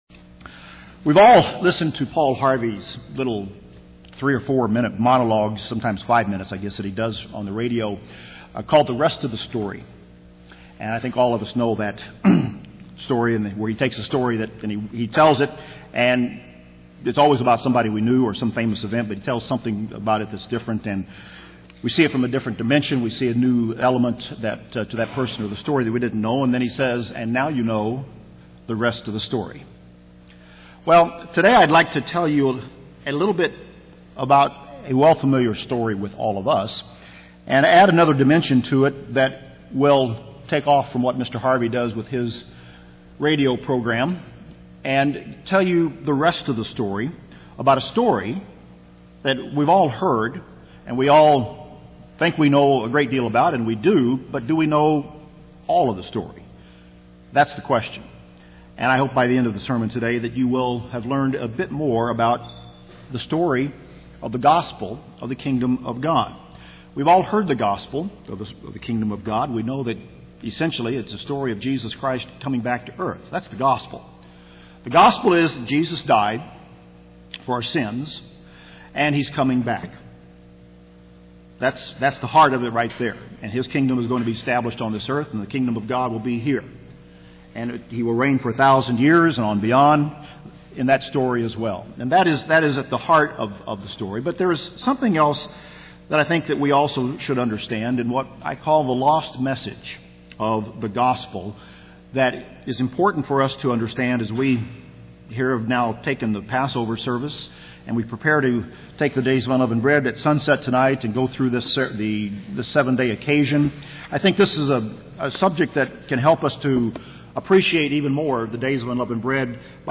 This encouraging sermon focuses on a parable of seed sown to those of a noble and good heart.